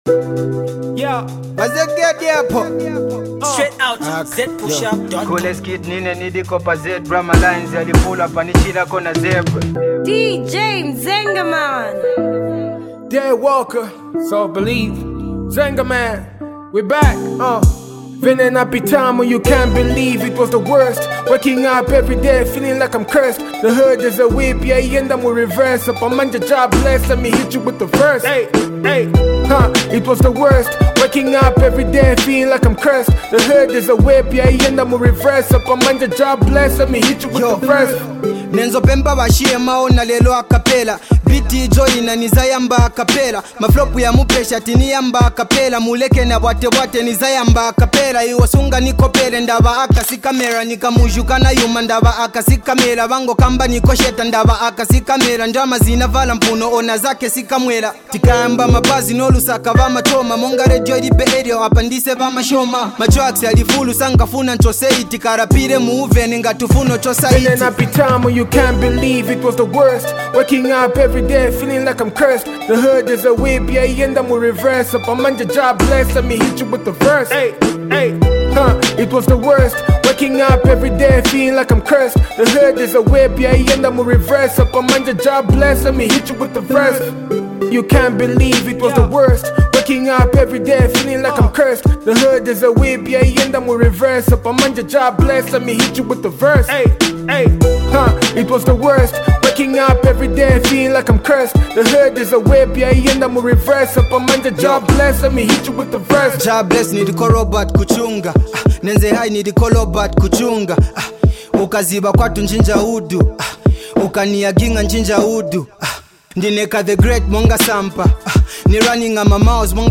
hip-hop
real hip-hop